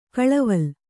♪ kaḷaval